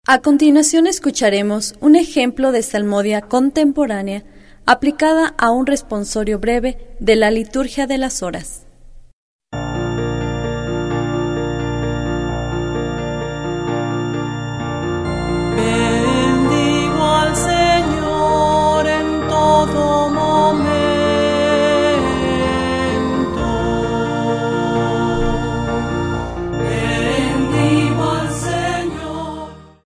10 Ejemplo de salmodia contemporanea.